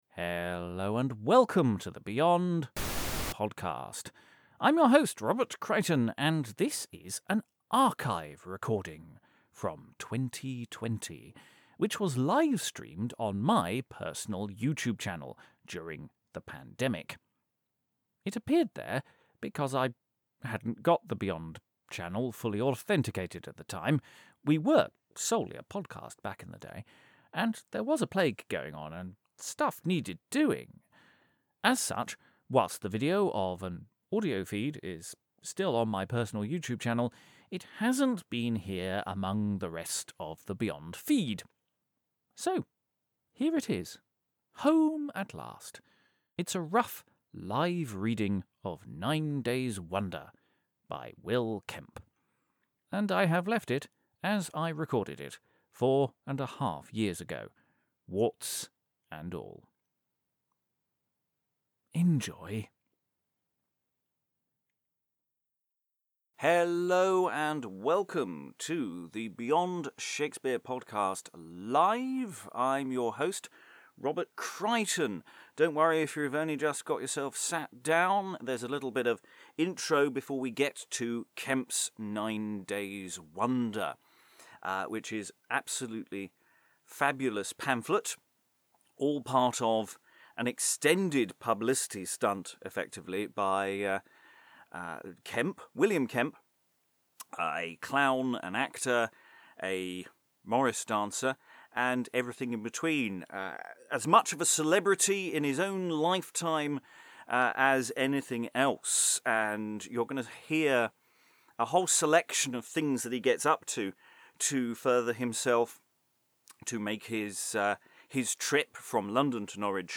Apart from some cuts advertising now very past future events, the recording has been left as it was recorded on the afternoon of Friday 20th March 2020. Will Kemp, an actor and clown, decides to hold a publicity stunt and cash in on his fame by Morris dancing all the way from London to Norwich.